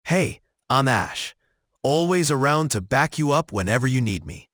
NavTalk 提供多种高质量的语音合成风格，您可以通过 voice 参数自由选择数字人音色：
稳重可信的男声